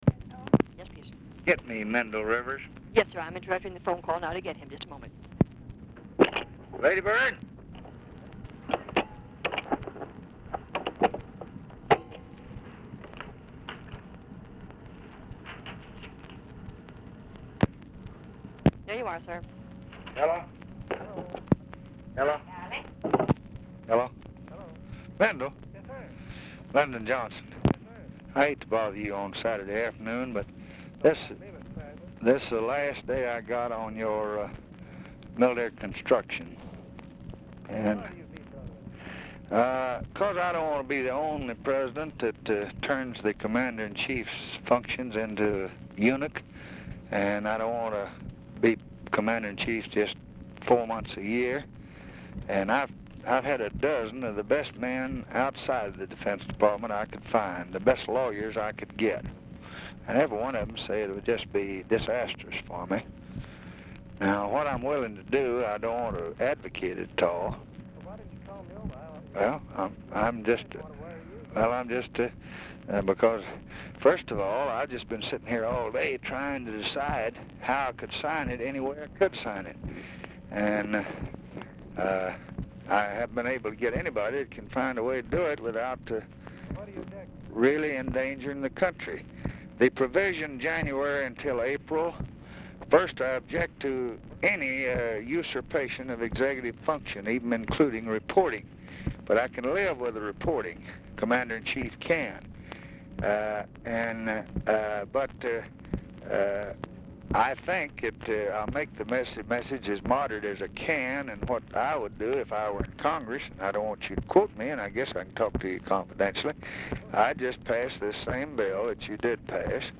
Telephone conversation
BRIEF OFFICE CONVERSATION PRECEDES CALL; LADY BIRD JOHNSON IS MEETING WITH LBJ AT TIME OF CALL AND IS AUDIBLE IN BACKGROUND; RIVERS IS ALMOST INAUDIBLE
Dictation belt